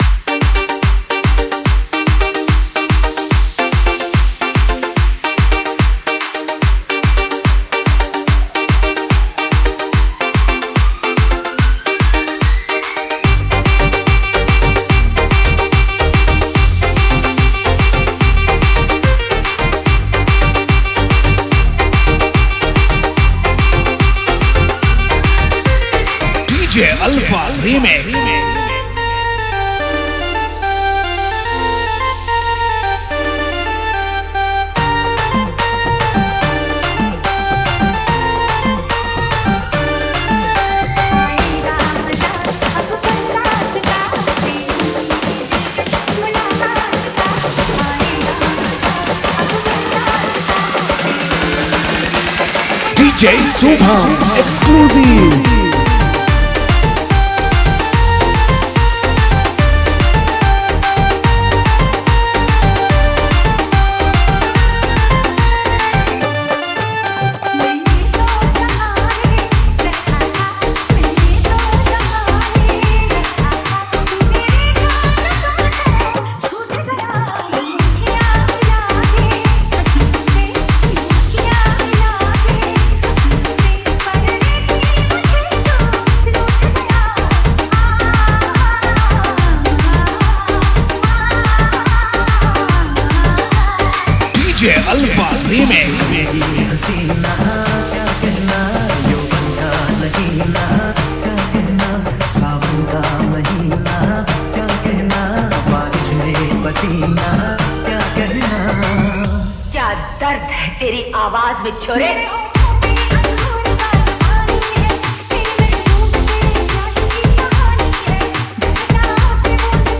Category: EDM Vibration Dj Remix Songs